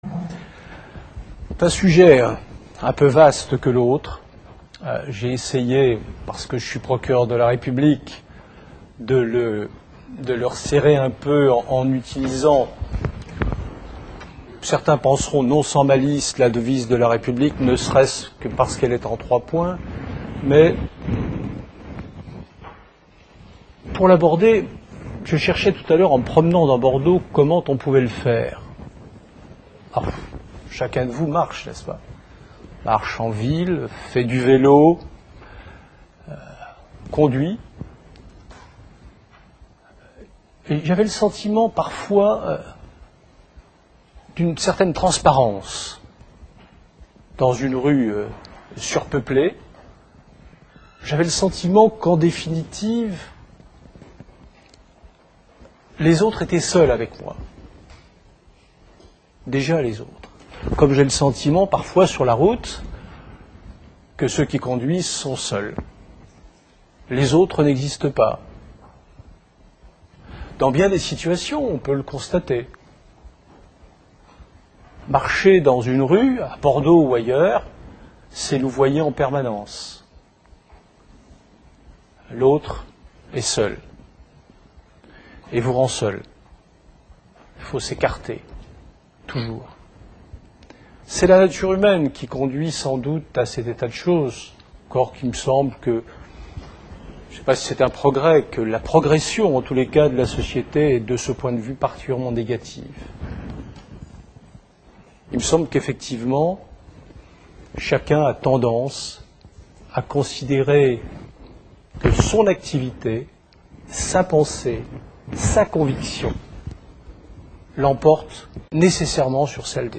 Il dépend de la volonté de chaque citoyen de s’emparer de cette devise et de trouver une solution à sa condamnation. La conférence a été donnée à l'Université Victor Segalen Bordeaux 2 dans le cadre du cycle de conférences "L'invité du Mercredi" / Saison 2006-2007 sur le thème "L'autre".